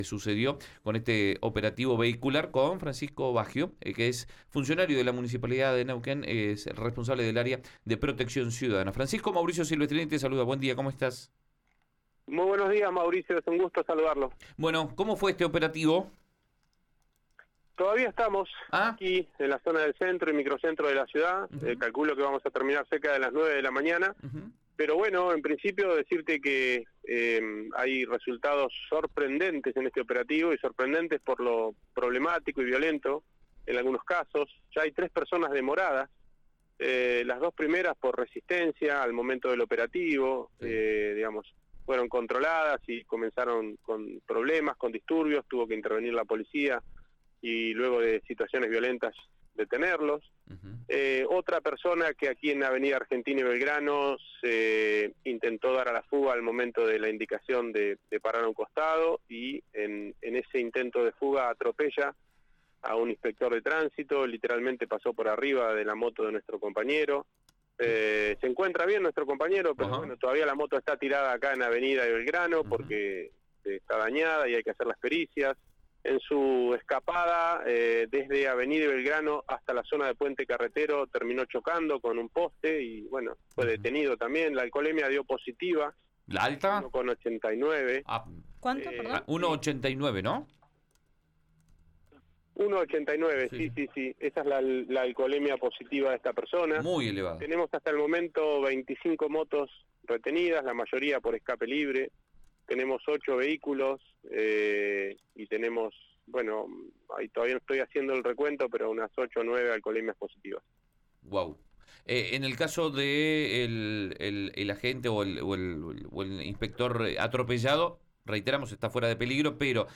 En diálogo con Panorama Informativo, Baggio dijo que hubo «resultados sorprendentes» durante los controles que se realizaron en conjunto con la Policía.